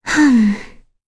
Kirze-Vox_Sigh.wav